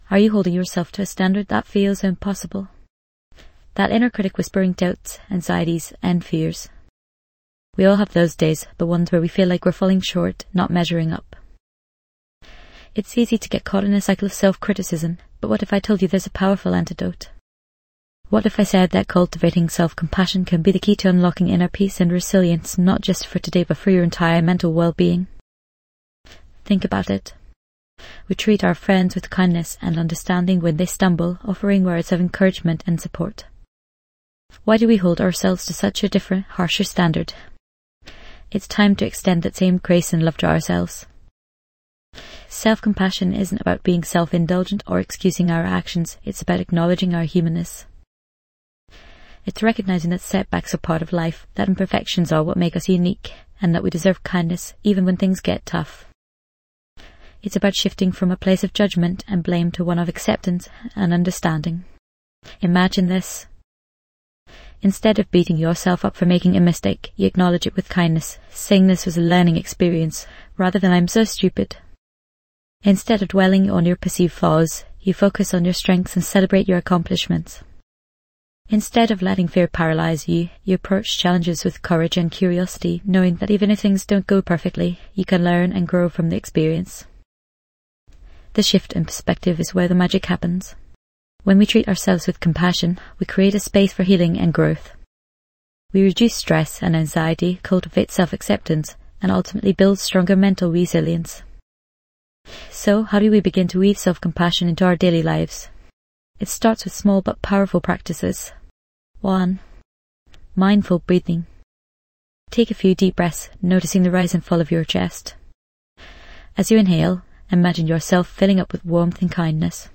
'Gratitude Recharge: Fast Daily Practice' delivers bite-sized gratitude meditations, perfect for busy lives.